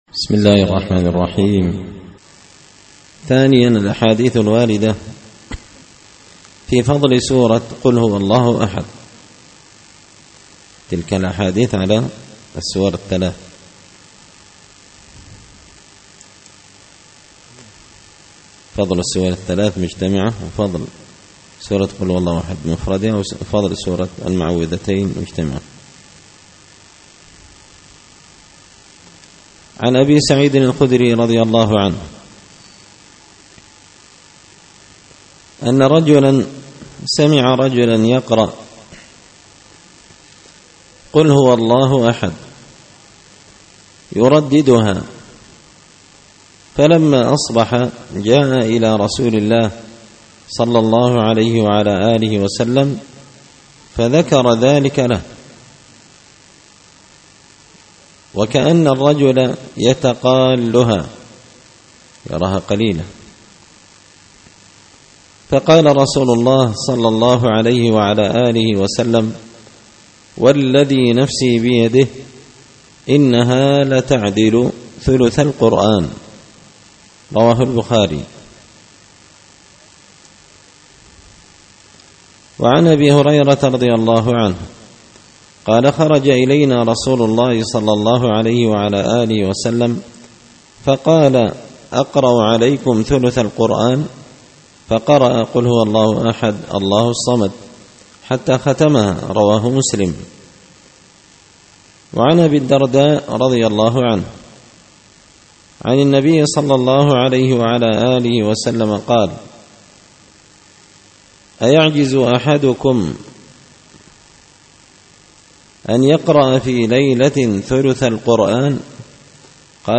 الأحاديث الحسان فيما صح من فضائل سور القرآن ـ الدرس السابع والخمسون